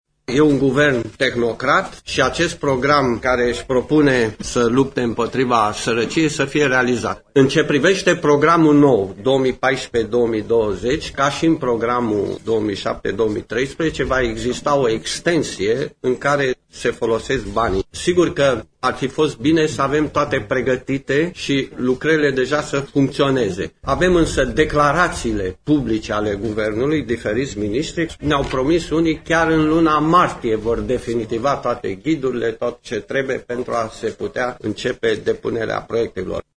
Într-o conferință de presă, Teodor Stolojan a apreciat în nume propriu că nu are încredere în vicepremierul Vasile Dâncu deoarece a anunțat lansarea unor programe de combatere a sărăciei, iar acest lucru nu s-a întâmplat: